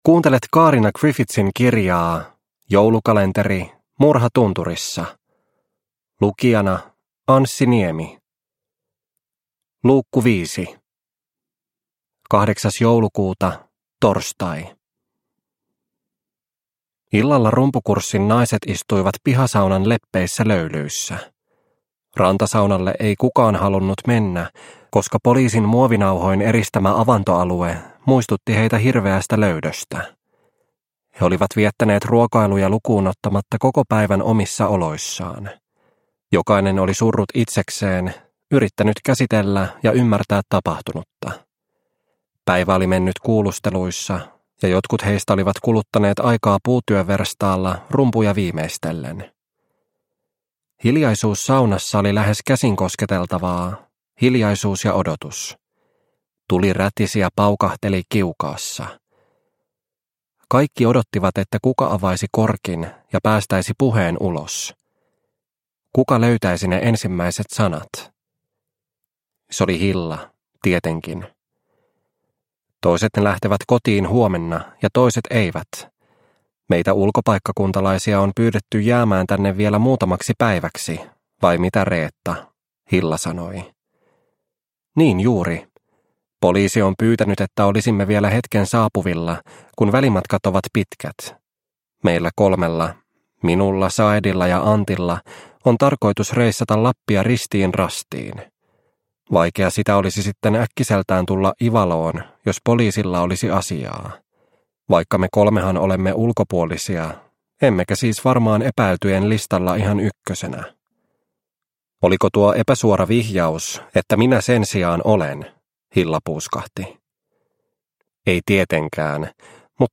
Murha tunturissa - Osa 5 – Ljudbok – Laddas ner